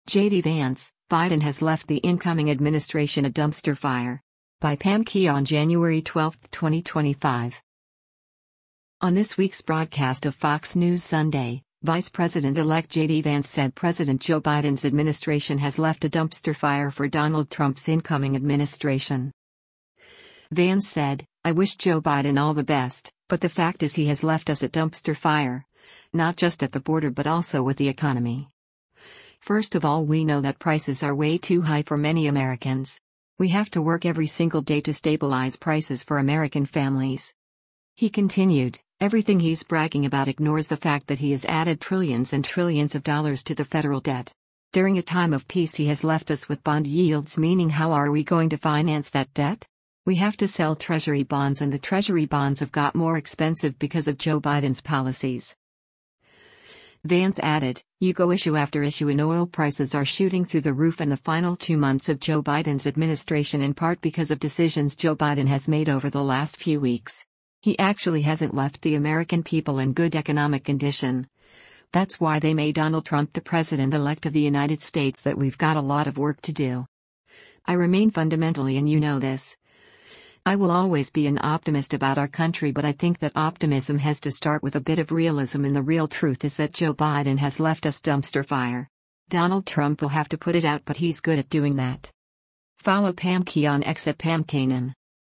On this week’s broadcast of “Fox News Sunday,” Vice President-elect JD Vance said President Joe Biden’s administration has left a “dumpster fire” for Donald Trump’s incoming administration.